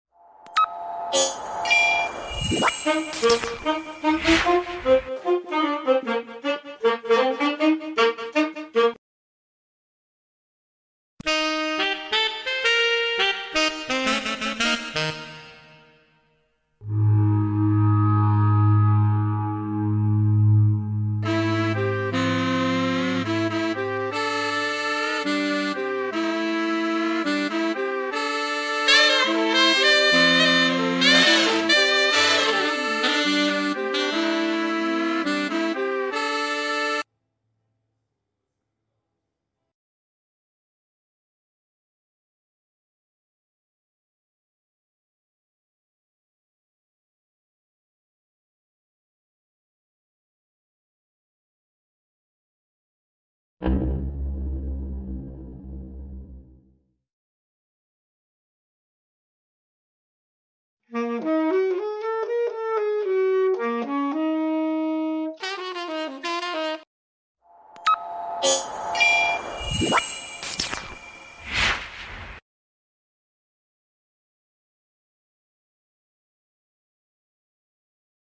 Royalty Free Saxophone Sounds Perfect sound effects free download